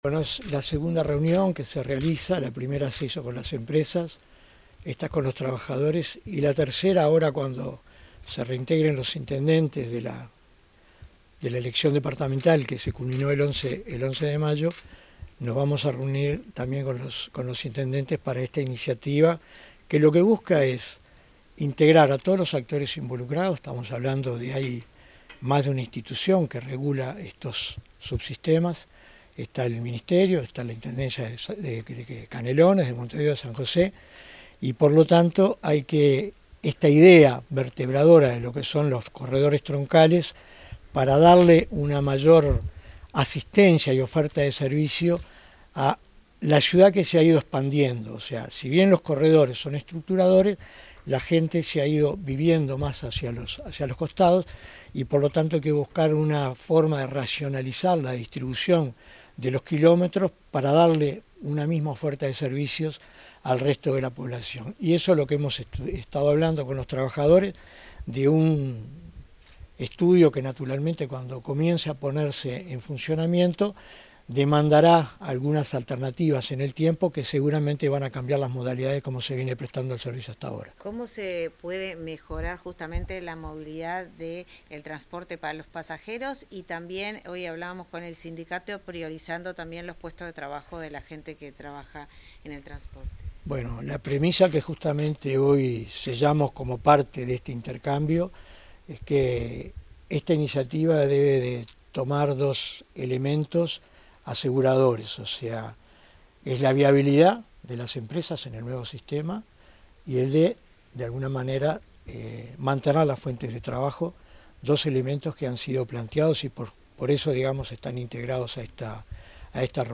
Declaraciones del director nacional de Transporte, Felipe Martín